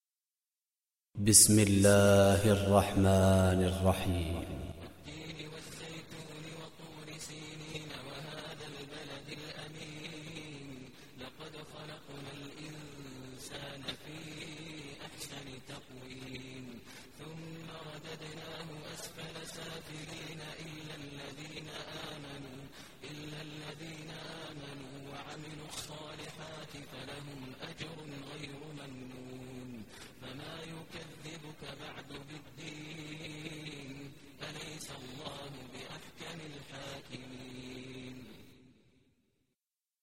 Surah Tin Recitation by Sheikh Maher Mueaqly
Surah Tin, listen online mp3 tilawat / recitation in Arabic in the voice of Imam e Kaaba Sheikh Maher al Mueaqly.